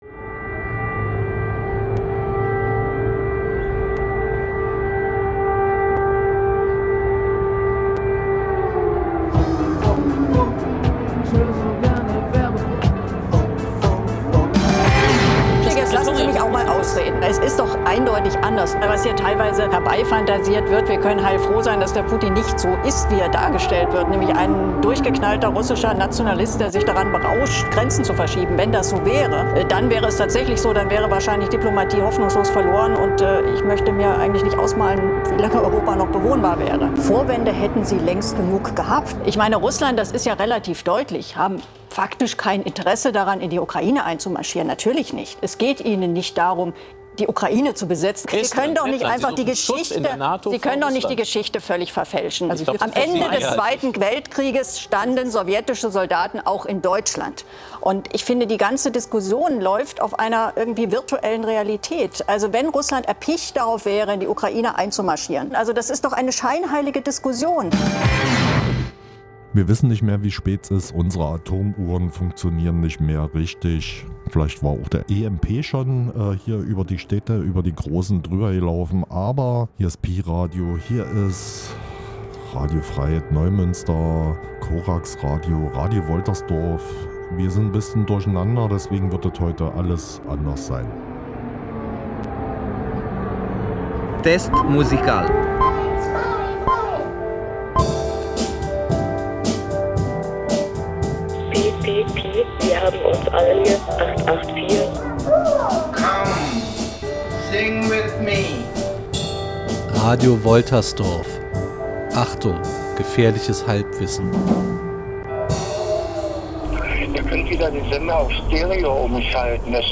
Die Sendung wurde am 27. Februar 2022 vorproduziert und spiegelt nicht die aktuelle Kriegssituation wieder.